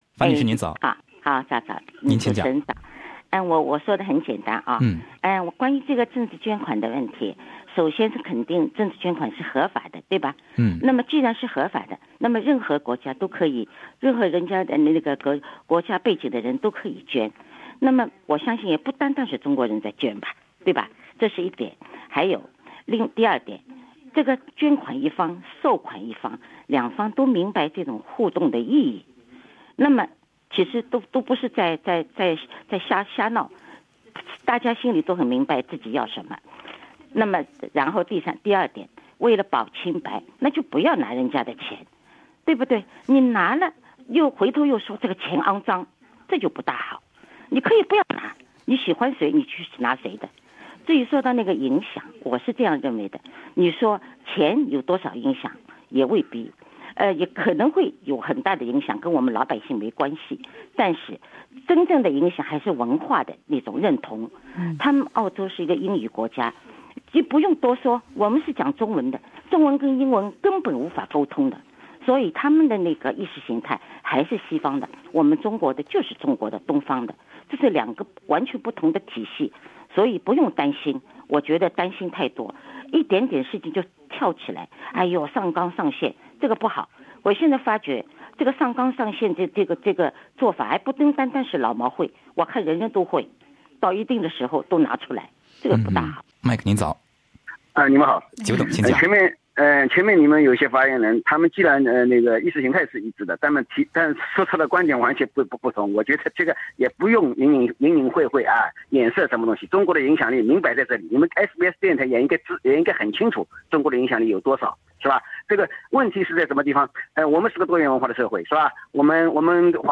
本台正在行動節目的一些聽眾在熱線中表達了自己的觀點，認為上綱上線不好，彆把中國政治文化搞到澳洲來。